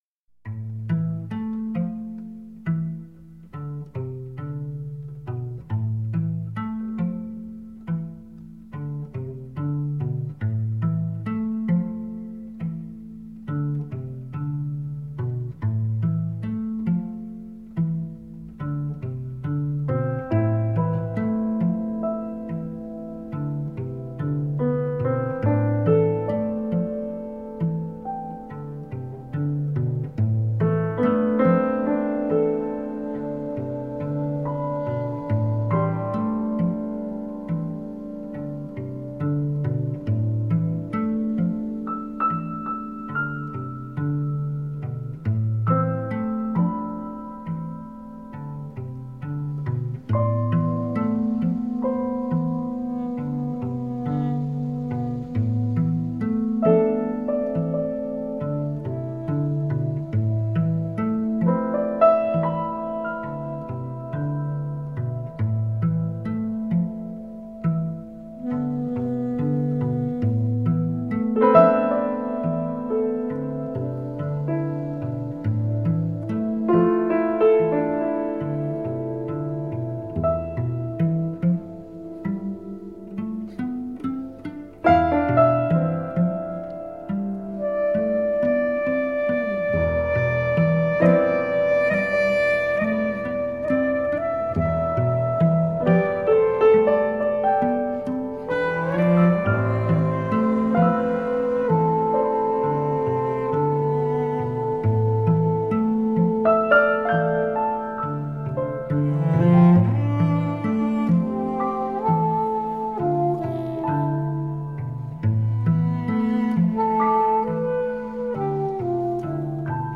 Dreamy music
Tagged as: New Age, Ambient, Cello, Ethereal